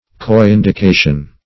Search Result for " coindication" : The Collaborative International Dictionary of English v.0.48: Coindication \Co*in`di*ca"tion\, n. [Cf. F. co["i]dication.]